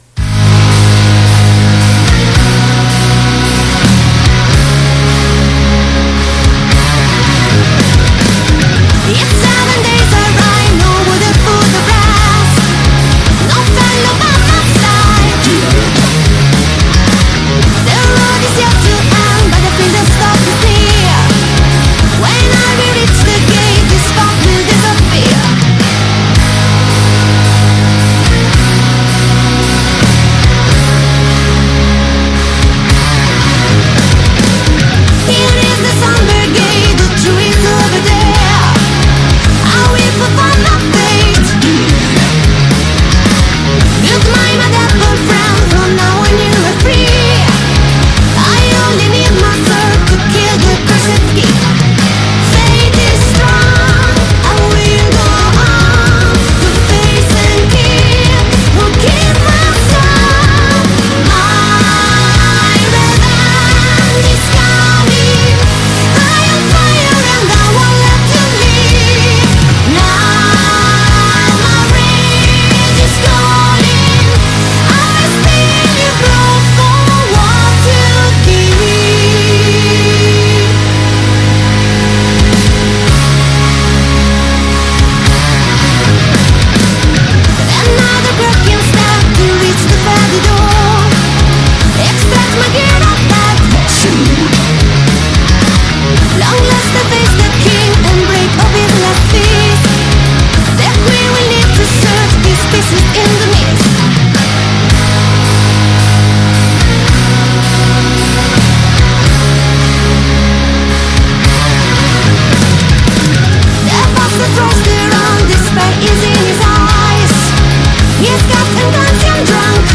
Intervista agli Eveline | 30-1-23 | Radio Città Aperta